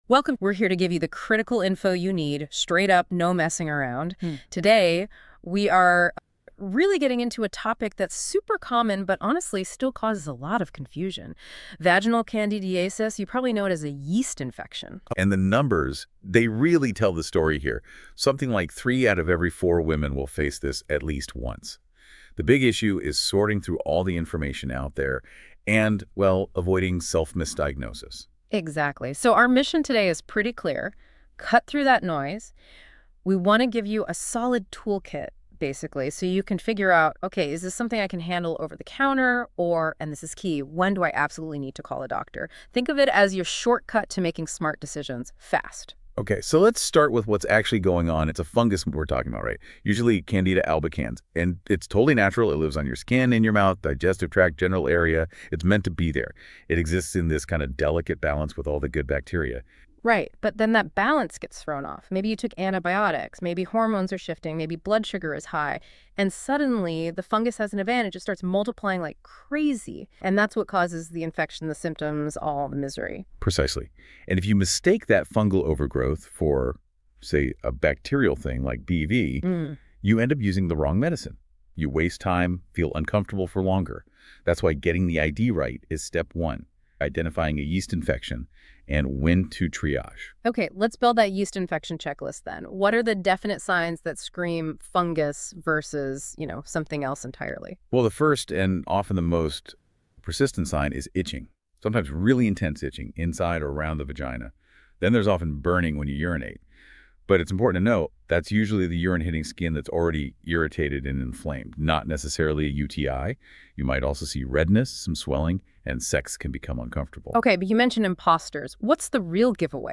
Listen to a discussion about when to see a doctor for a yeast infection When should I see a doctor for a yeast infection?